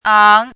拼音： áng
注音： ㄤˊ
ang2.mp3